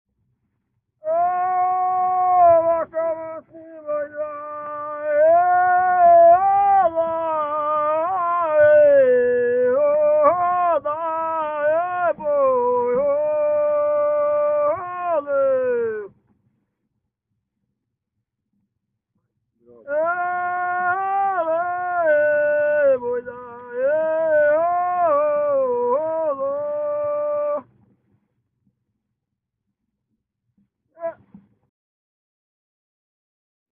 Aboio